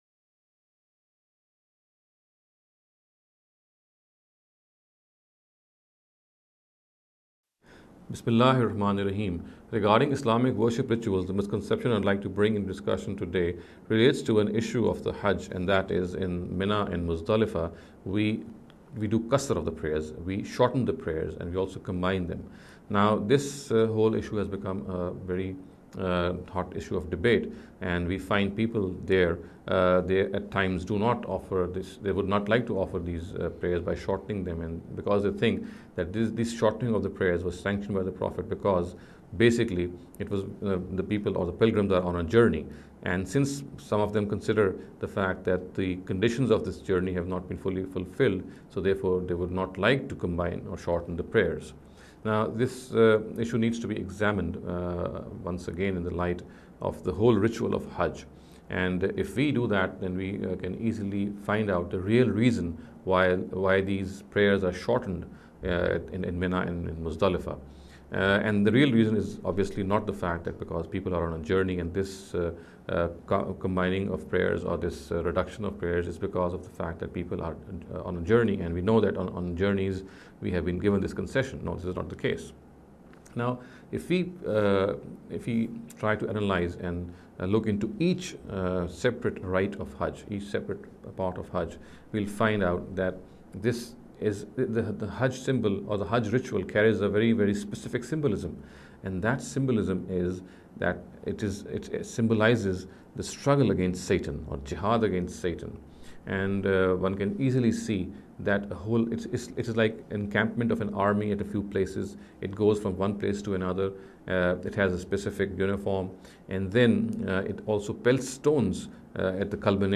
This lecture series will deal with some misconception regarding the Islamic Worship Rituals.